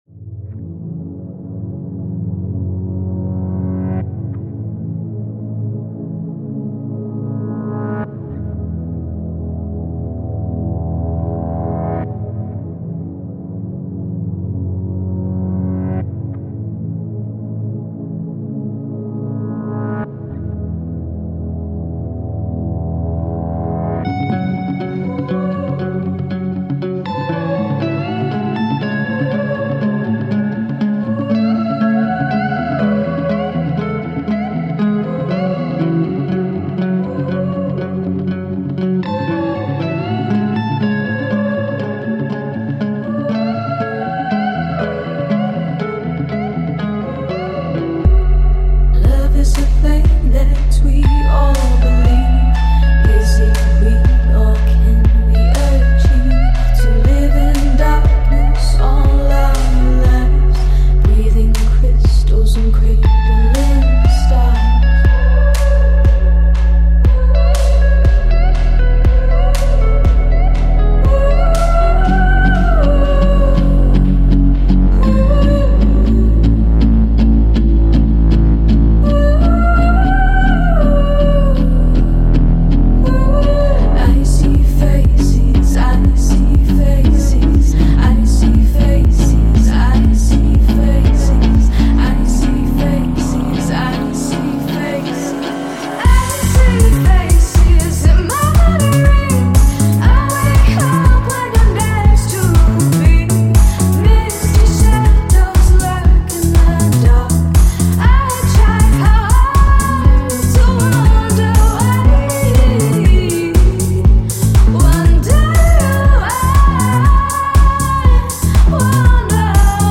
Manchester UK electronic pop trio